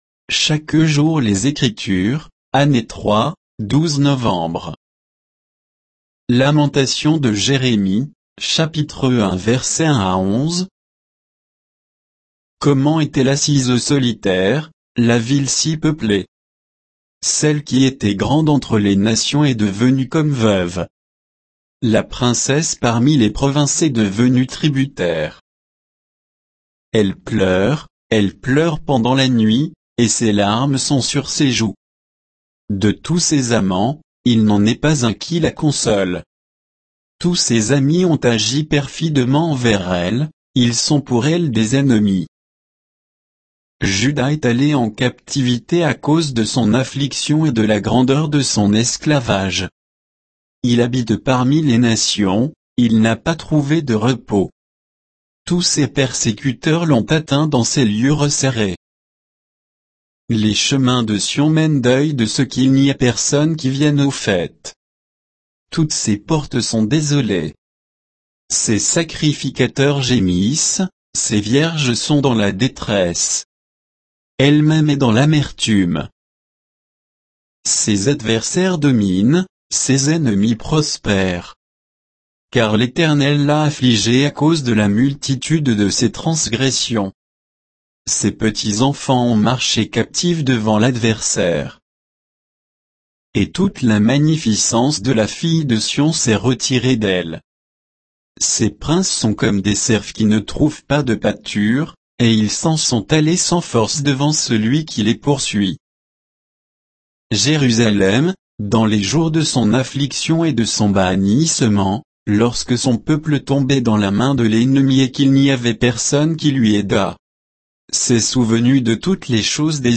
Méditation quoditienne de Chaque jour les Écritures sur Lamentations de Jérémie 1